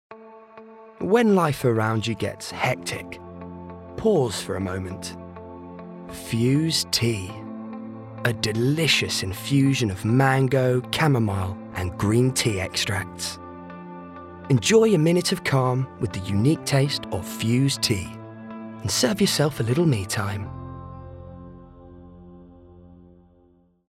Tyger has a neutral RP accent with an upbeat, youthful and enthusiastic tone to his voice.
• Male